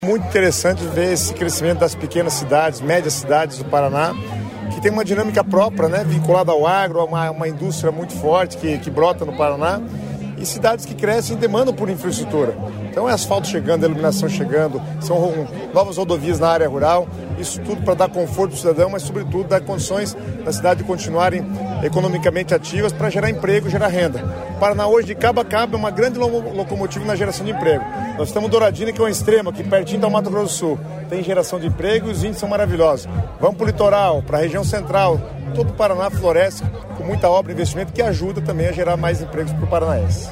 Sonora do secretário Estadual das Cidades, Guto Silva sobre a pavimentação de estrada que liga sede a distrito de Douradina